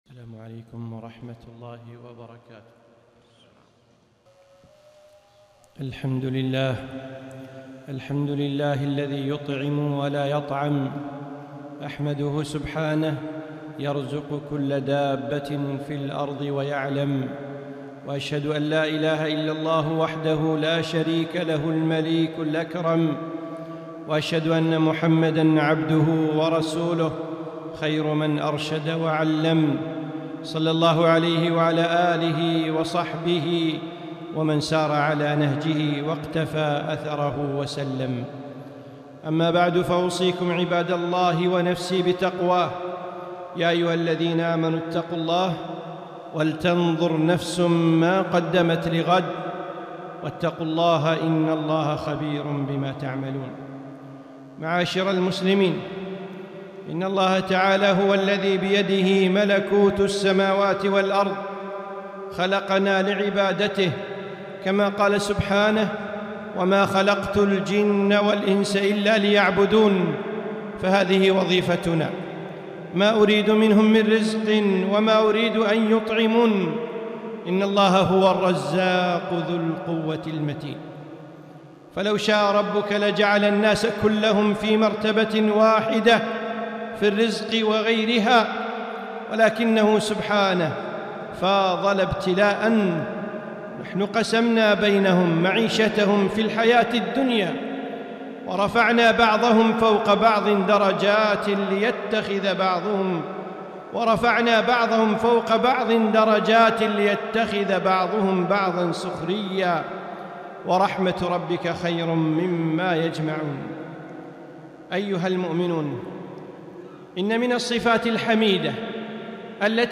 خطبة - أطعموا